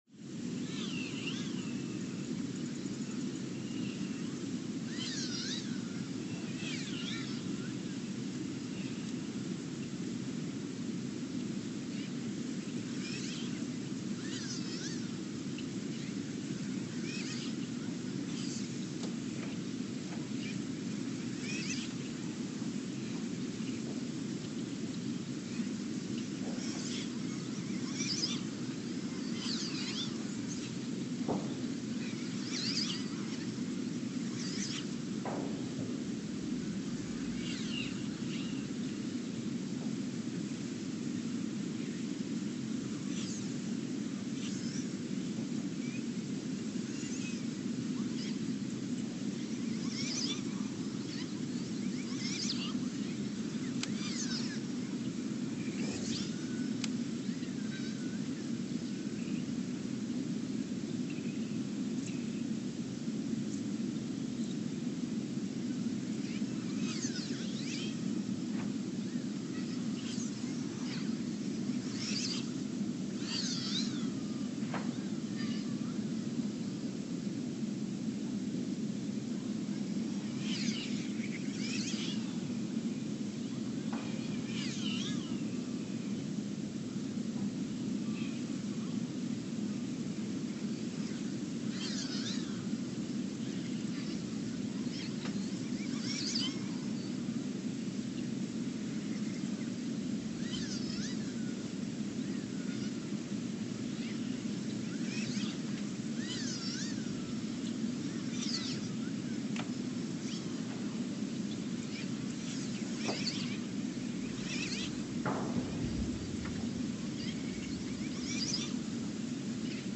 Ulaanbaatar, Mongolia (seismic) archived on July 13, 2023
No events.
Sensor : STS-1V/VBB
Speedup : ×900 (transposed up about 10 octaves)
Loop duration (audio) : 03:12 (stereo)
SoX post-processing : highpass -2 90 highpass -2 90